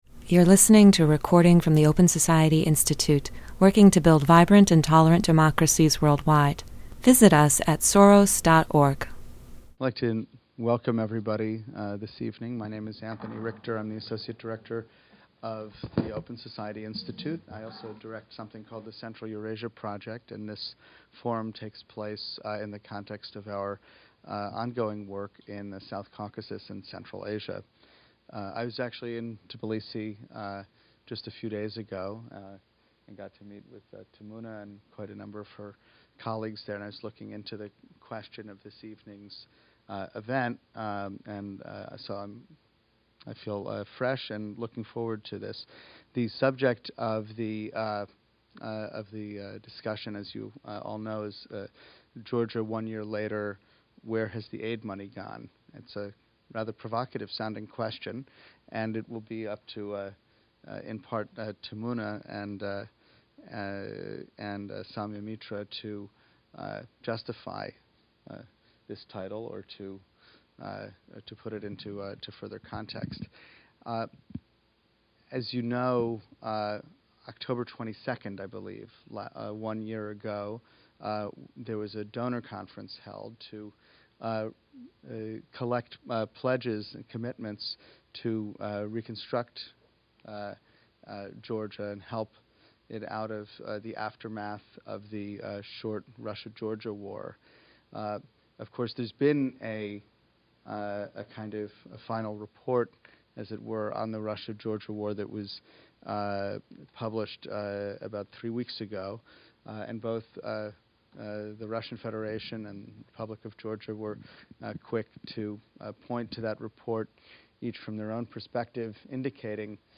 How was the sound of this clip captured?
This Open Society Institute forum analyzes the effectiveness and transparency of foreign aid disbursed to Georgia since the country's crisis with Russia.